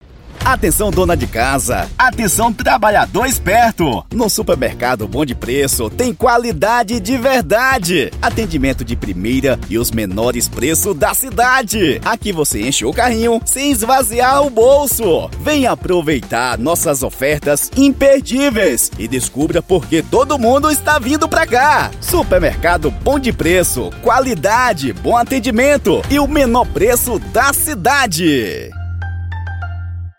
DEMONSTRATIVO PARA SUPERMERCADO:
Spot Comercial
Animada